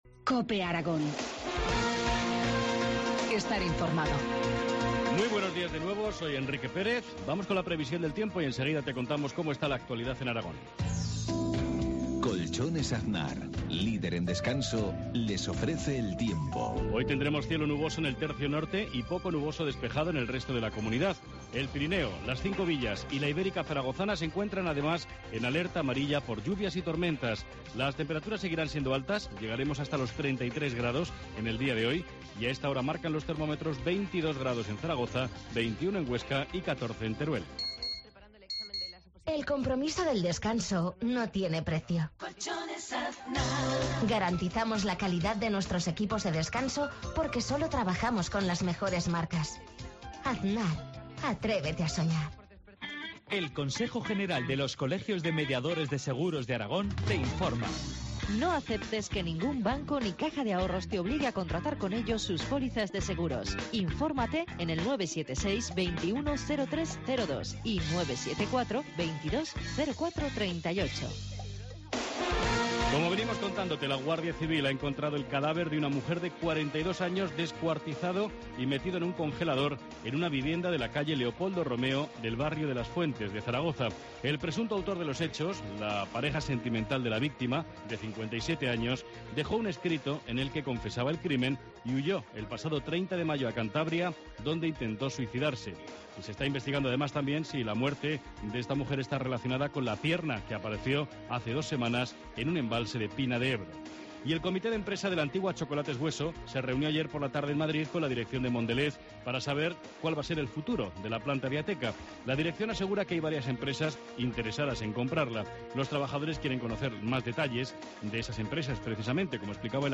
Informativo matinal, jueves 13 de junio, 7.53 horas